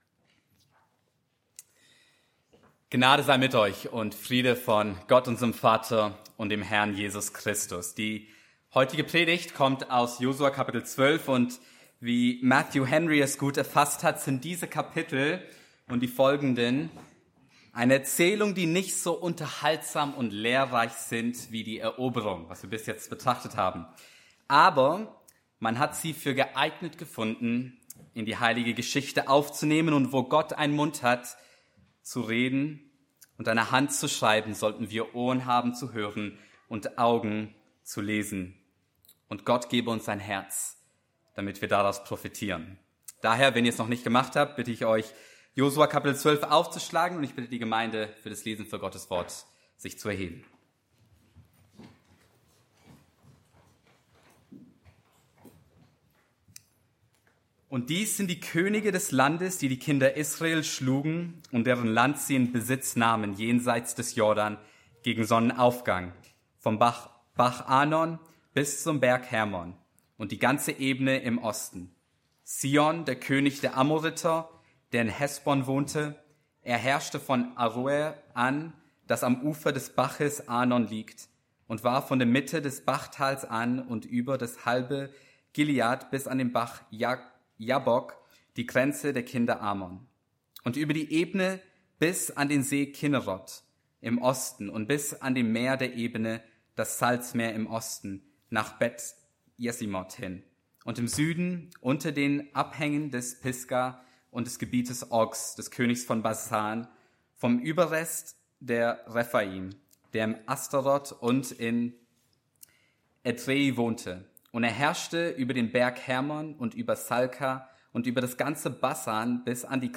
Auslegungsreihe